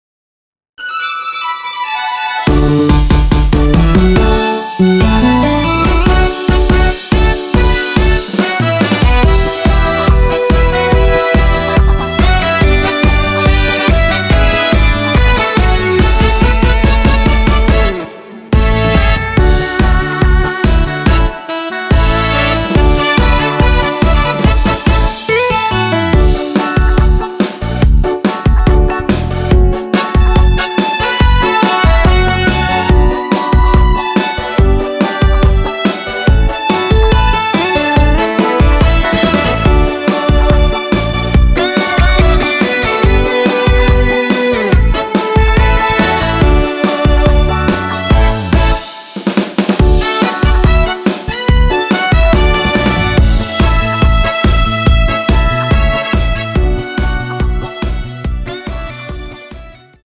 원키에서(-1)내린 MR입니다.
D
앞부분30초, 뒷부분30초씩 편집해서 올려 드리고 있습니다.